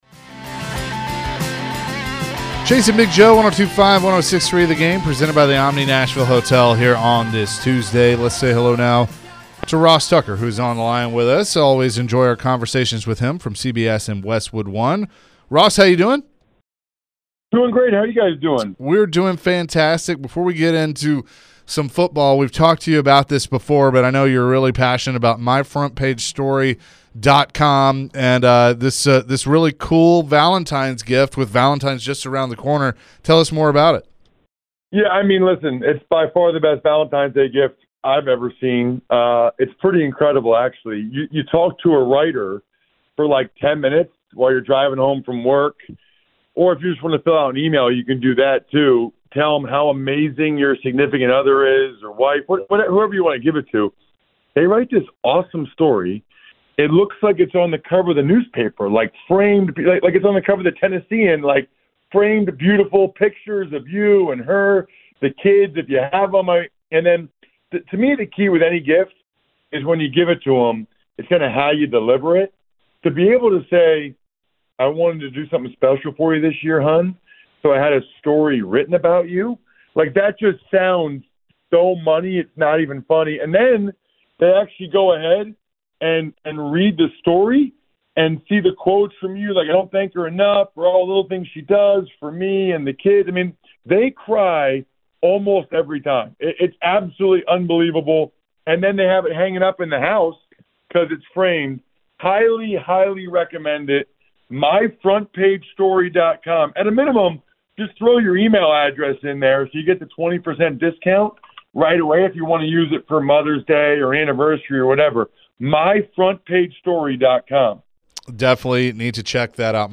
Ross Tucker with Westwood One and CBS joined the show and discussed the Titans recent moves to their staff and his overview of the roster.